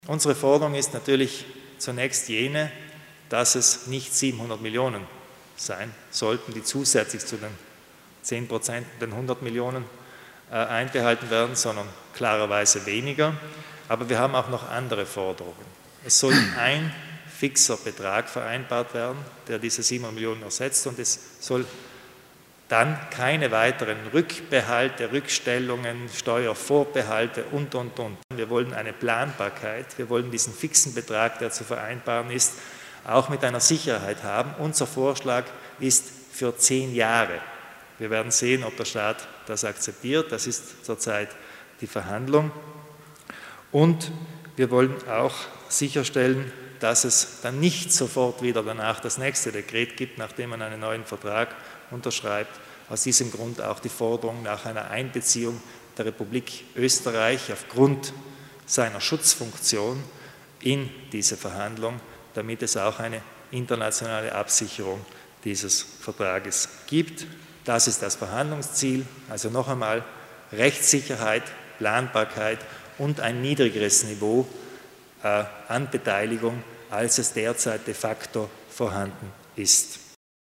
Landeshauptmann Kompatscher erklärt die Finanzverhandlungen zwischen Land und Staat